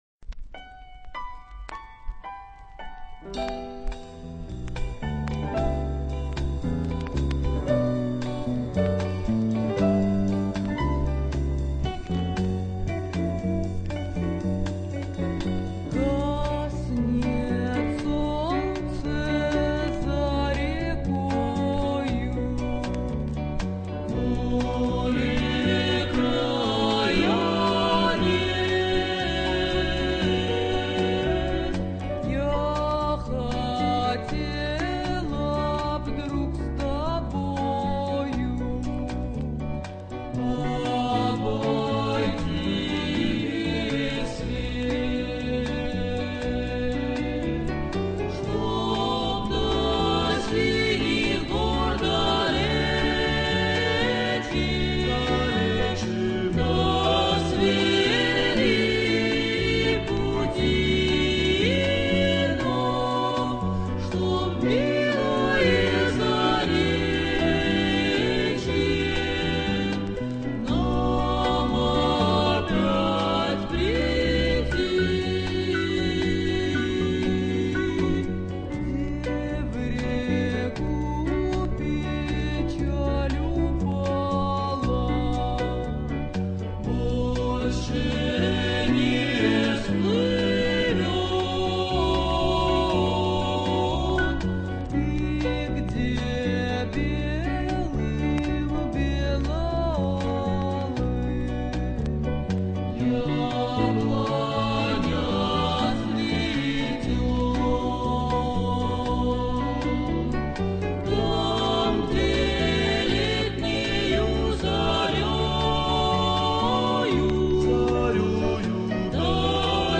по лёгкому акценту и манере пения